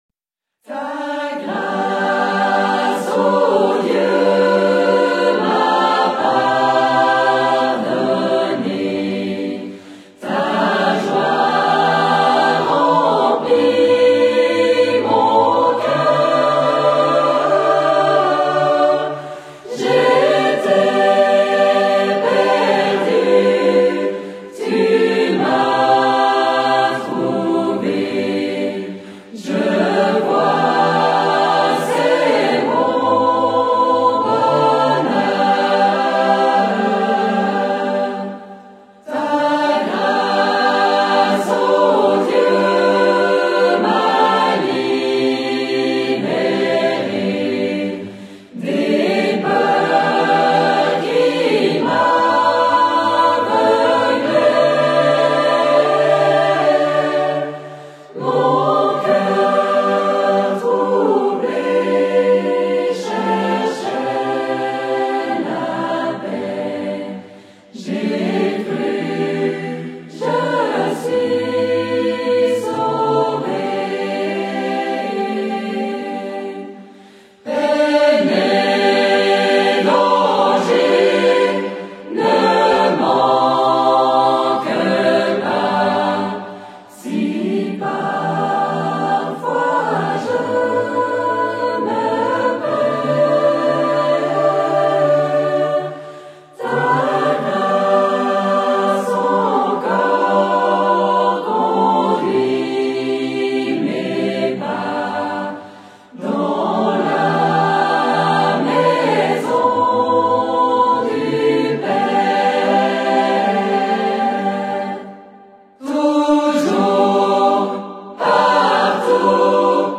Chœur d’hommes fondé en 1860
Hymne anglican
H10526-Live.mp3